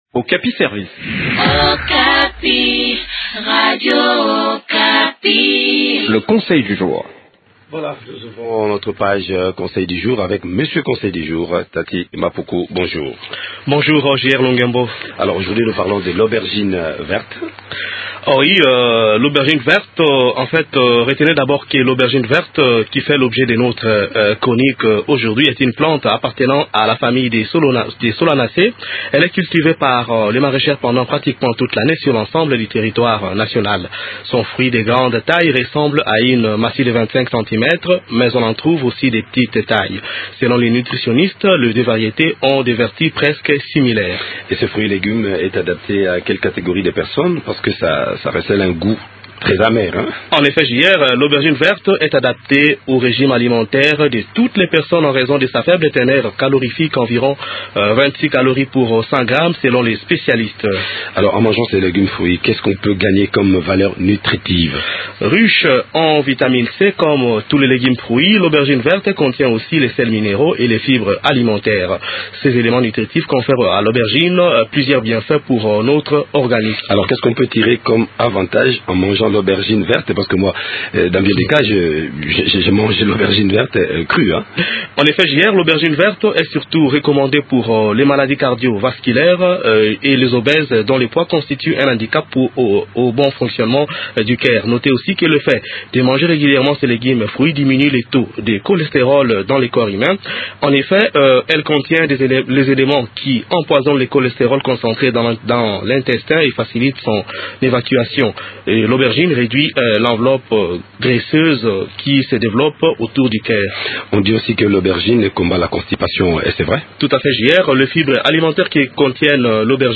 Découvrez les vertus de ce légume fruit dans ce compte rendu